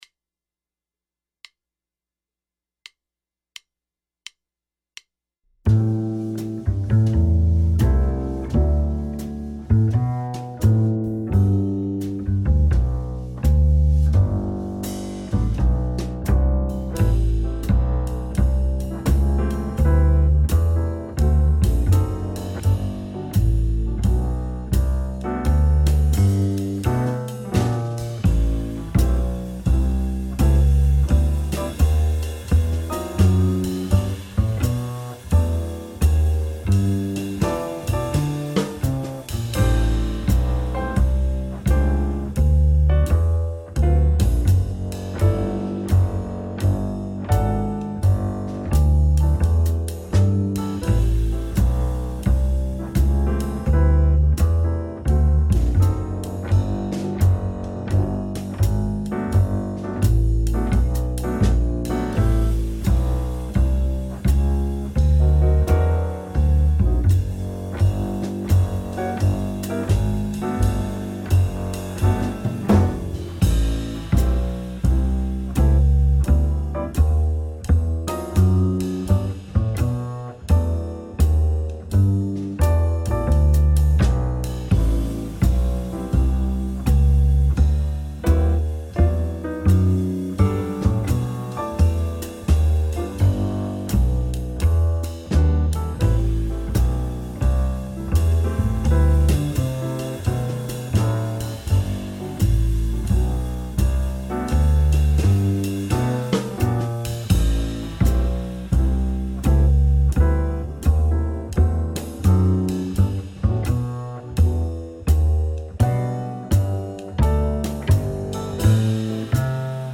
Major ii-V-I Arpeggios Workout